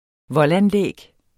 Udtale [ ˈvʌl- ]